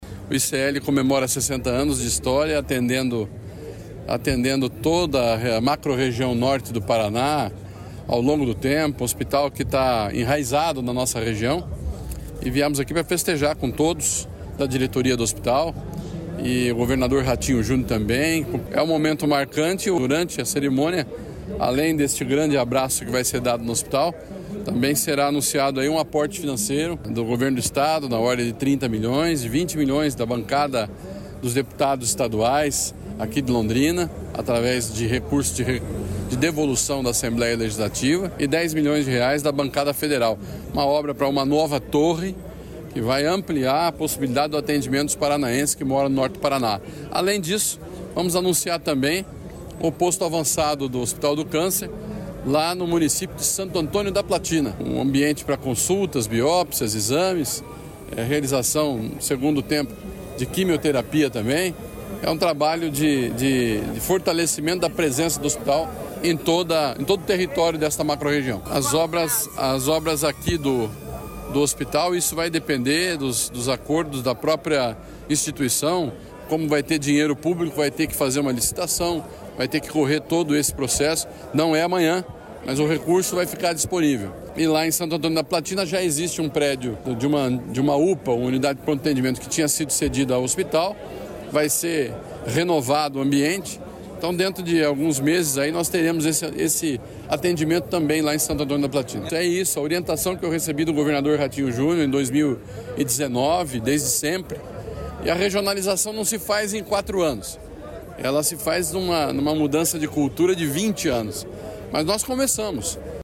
Sonora do secretário da Saúde, Beto Preto, sobre os investimentos no hospital de Londrina